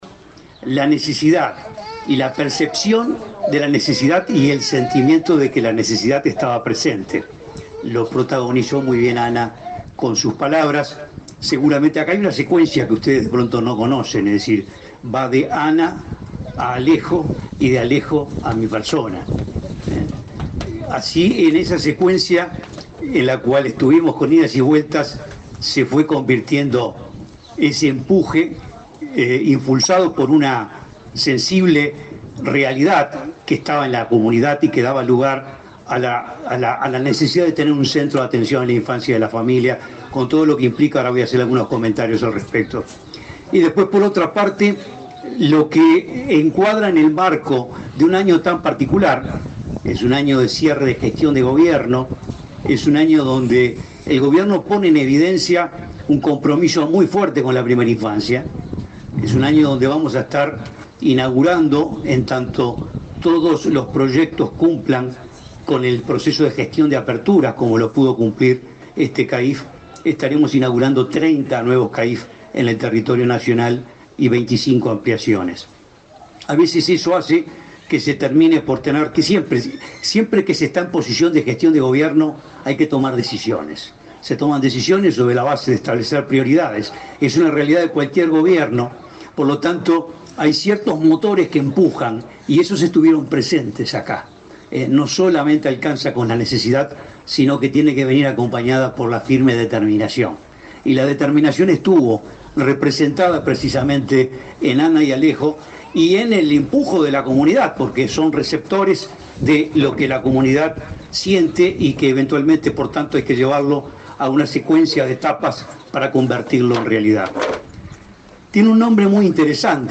Palabras del presidente del INAU, Guillermo Fosatti
El presidente del Instituto del Niño y Adolescente del Uruguay (INAU), Guillermo Fosatti, hizo uso de la palabra durante la inauguración del CAIF